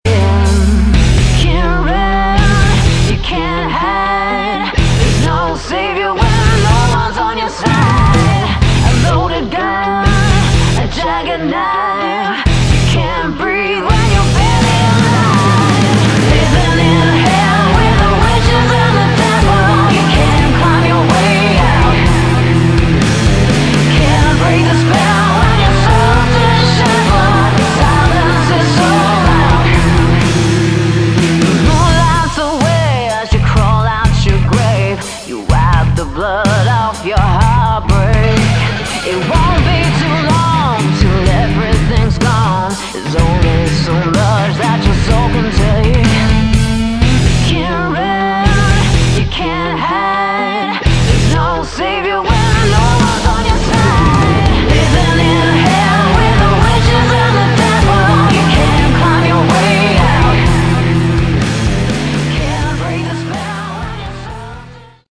Category: Melodic Rock
vocals, guitars
bass, guitar, keyboards, piano, violin, backing vocals
drums